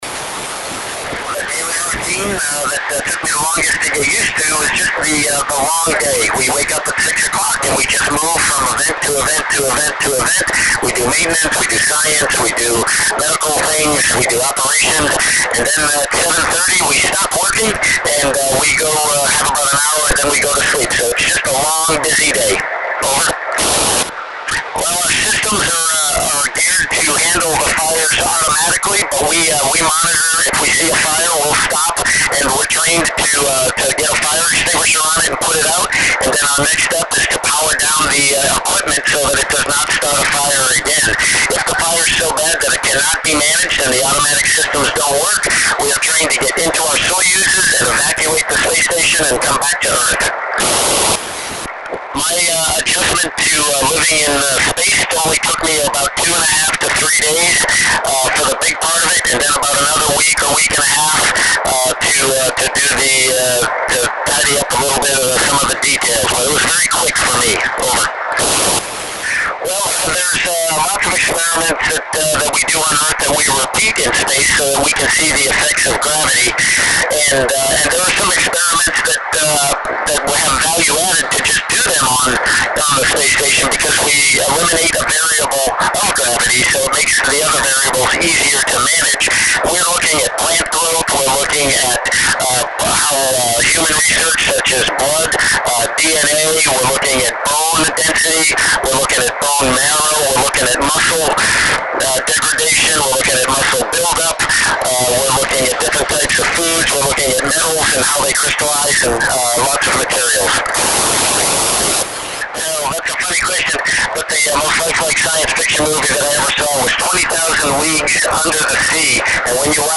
Fantastica recepción por IM98OL, Petrer (Alicante) Spain.